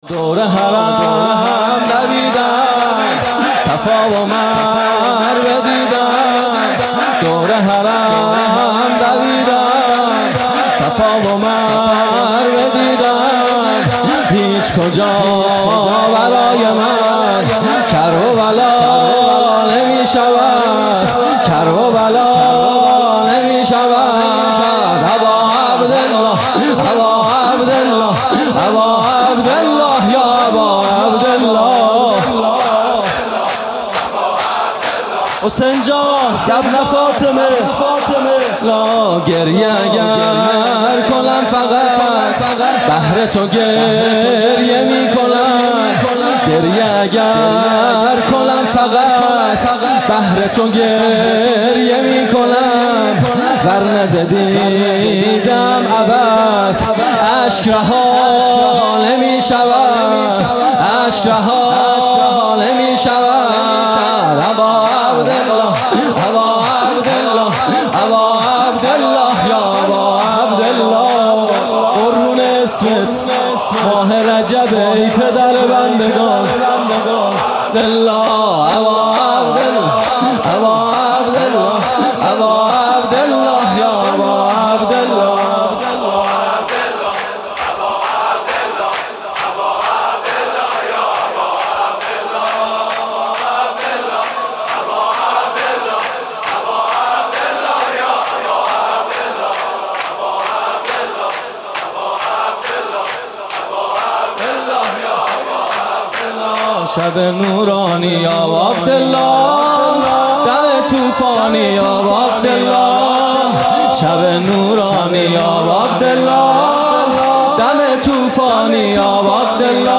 music-icon شور: شب بارونه صورتم خیسه...